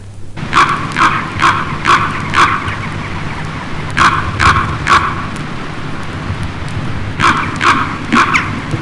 Grand Corbeau (bird) Sound Effect
Download a high-quality grand corbeau (bird) sound effect.
grand-corbeau-bird.mp3